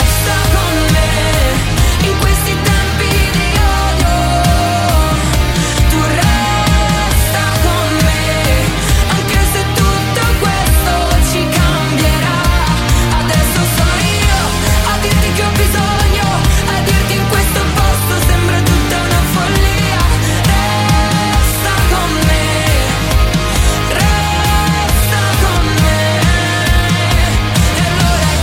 Genere: italiana,sanremo2026,pop.ballads,rap,hit